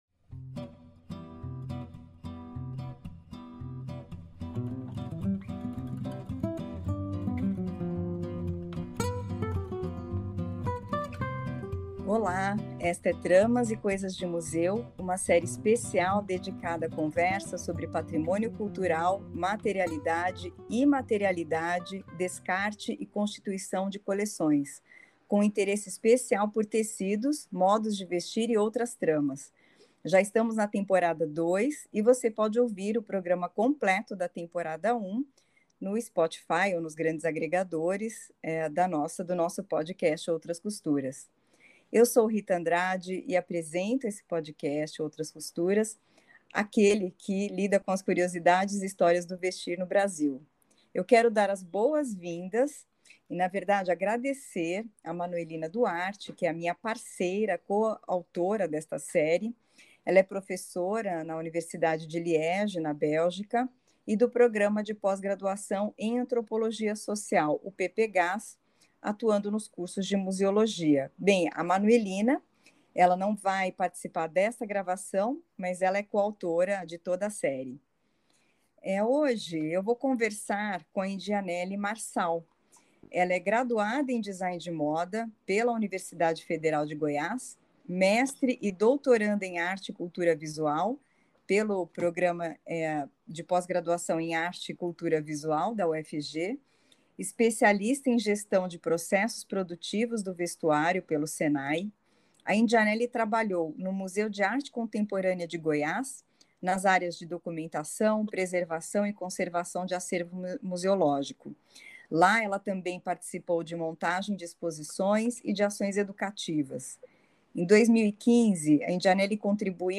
Send to RIS BibTex APA Chicago Permalink X Linkedin copy to clipboard copied Details Keywords : Museums; Material Culture; Costume; Research; Iny Karajá; Indigenous culture Abstract : [en] This series consists of a cycle of conversations about cultural heritage, materiality, immateriality, disposal and constitution of collections in museums and outside them. The idea is to reflect on controversial and current issues related to clothing and Brazilian identities related to public goods considered cultural heritage.